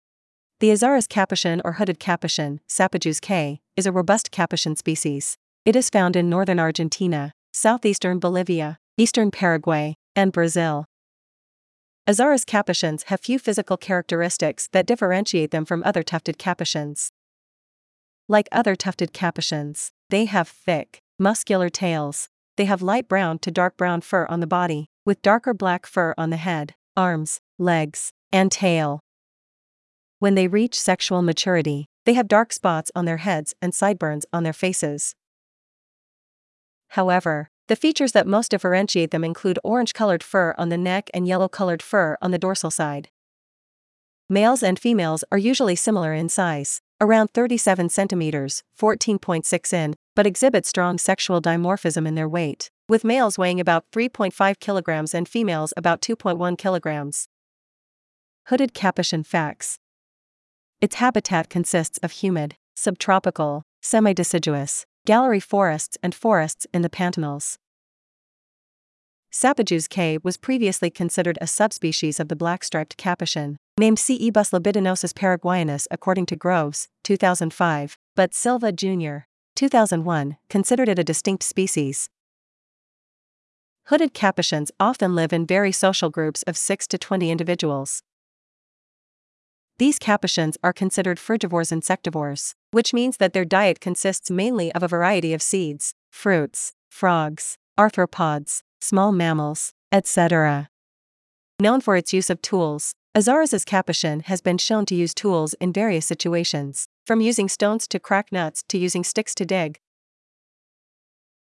hooded-capuchin.mp3